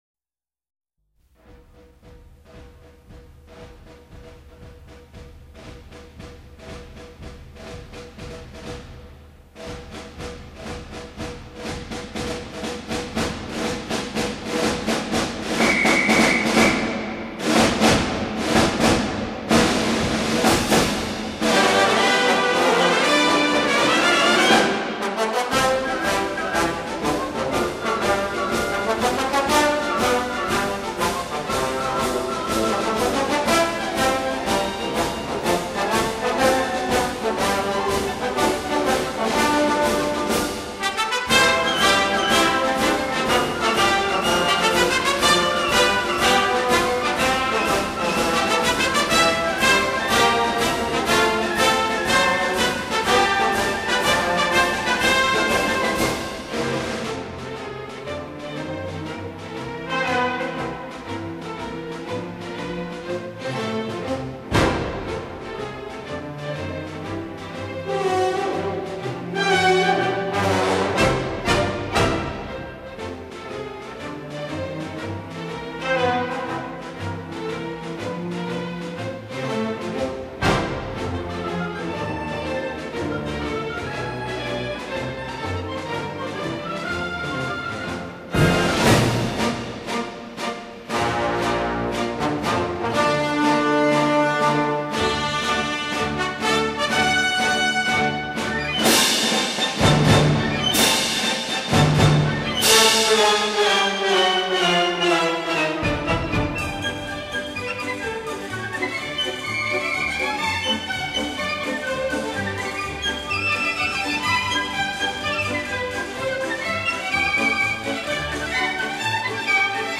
发行时间：2004年（录音：1958年）